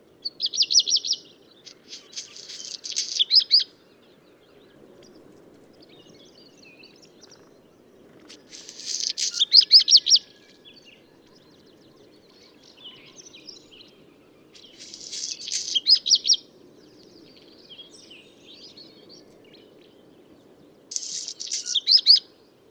Hausrotschwanz Gesang
Hausrotschwanz-Gesang-Voegel-in-Europa.wav